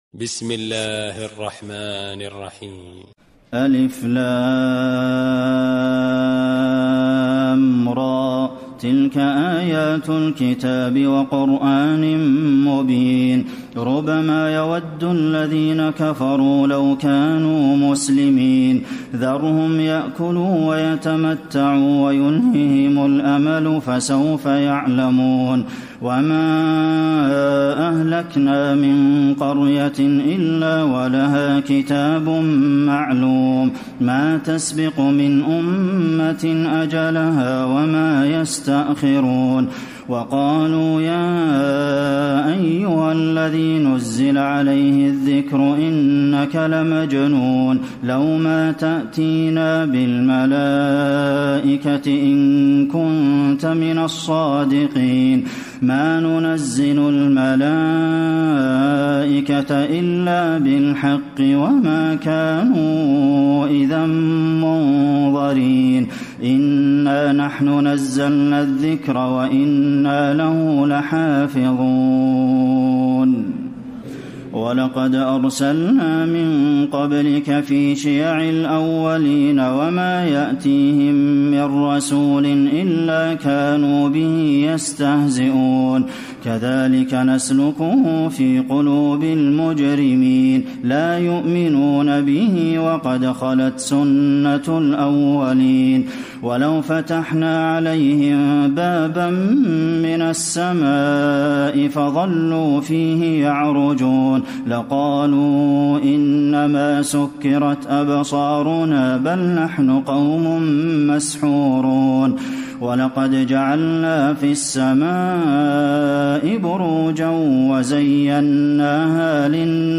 تراويح الليلة الثالثة عشر رمضان 1434هـ من سورتي الحجر كاملة و النحل (1-52) Taraweeh 13 st night Ramadan 1434H from Surah Al-Hijr and An-Nahl > تراويح الحرم النبوي عام 1434 🕌 > التراويح - تلاوات الحرمين